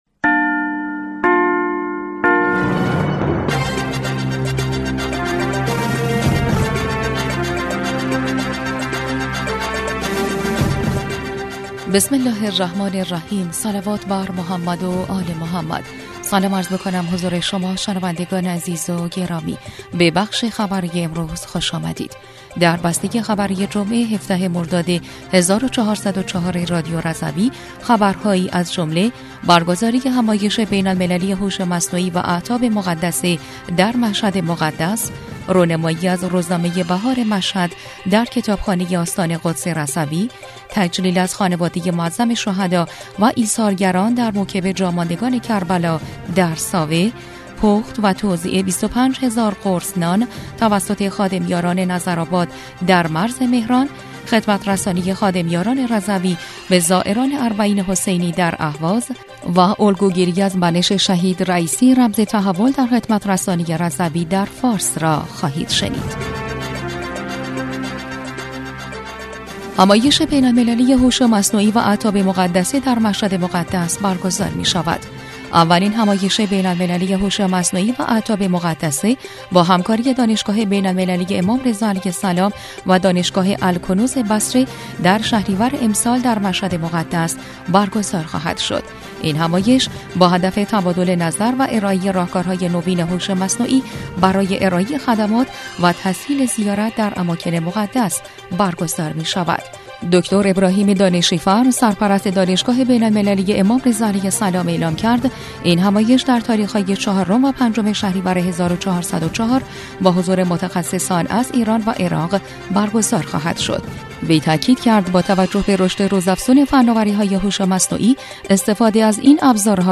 بسته خبری ۱۷ مردادماه ۱۴۰۴ رادیو رضوی؛